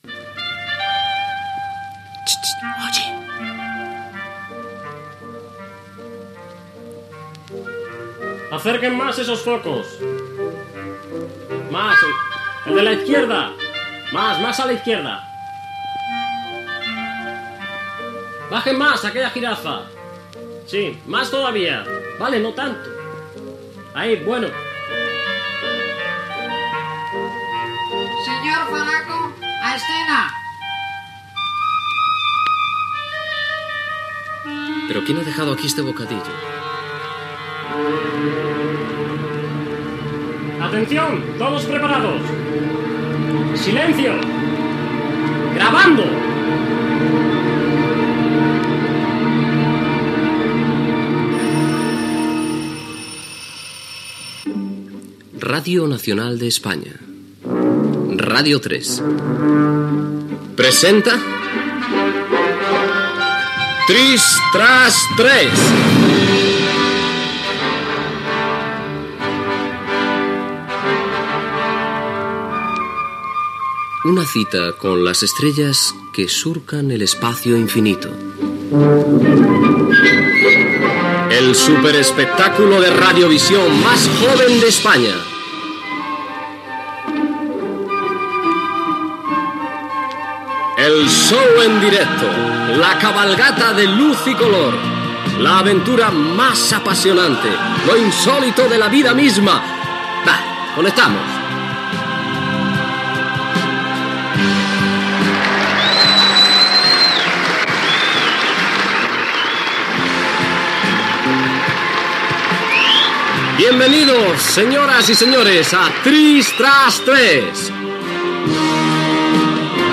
Preparatius en un plató per començar a rodar, careta del programa, enumeració d'invitats i de seccions, la veu
Entreteniment